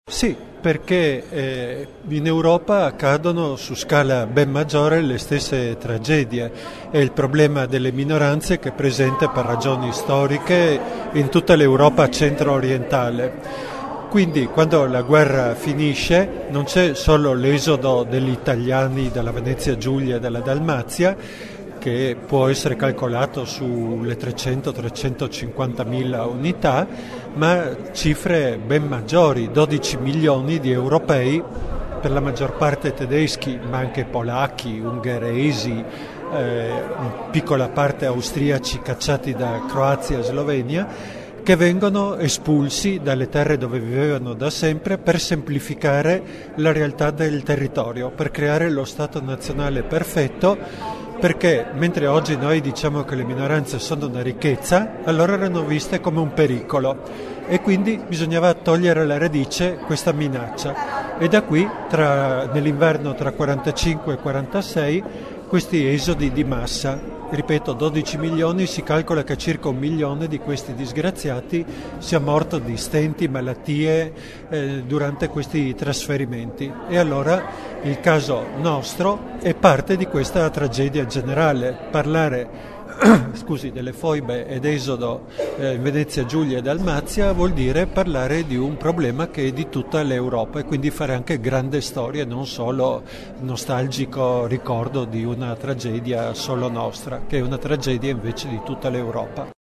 durante il consiglio comunale straordinario dedicato al “Giorno del Ricordo“.